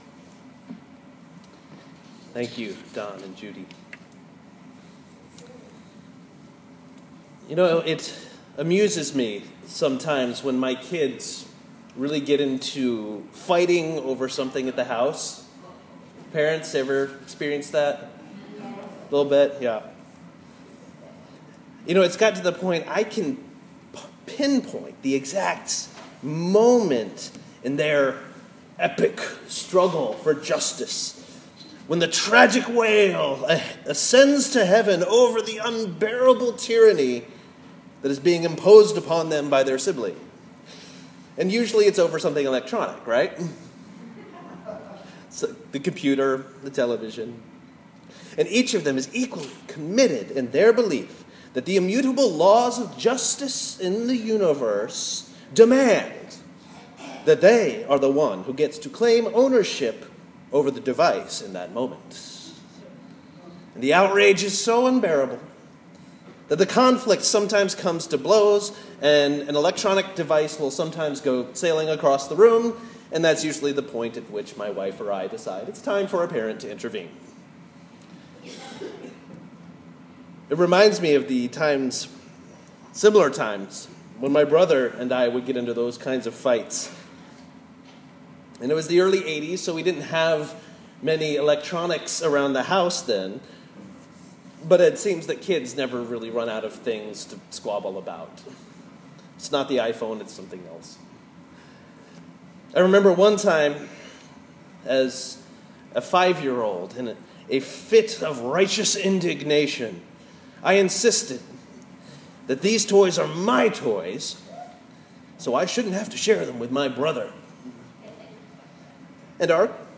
sermon-ordinary-7-a-2017.m4a